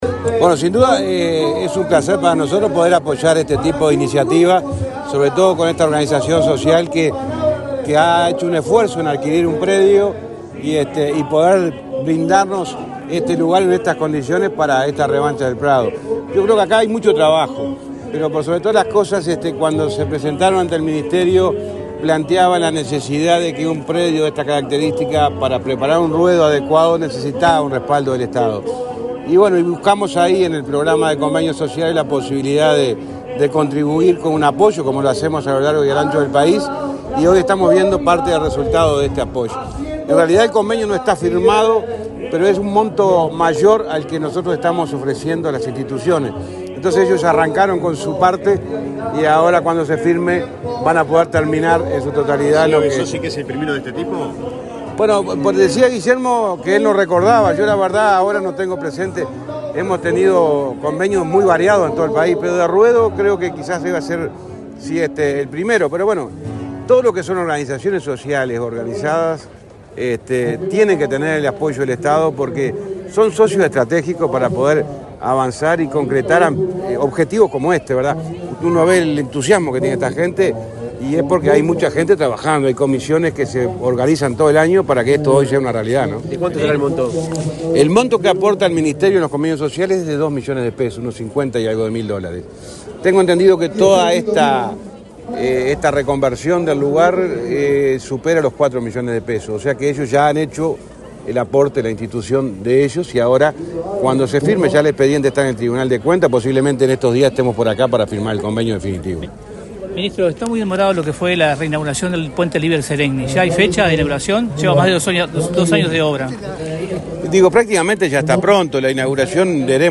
Declaraciones del ministro de Transporte, José Luis Falero 06/04/2024 Compartir Facebook X Copiar enlace WhatsApp LinkedIn El ministro de Transporte y Obras Públicas, José Luis Falero, fue entrevistado por medios informativos en Soriano, luego de participar de la apertura de la 62.ª edición de la Revancha Del Prado, en Cardona.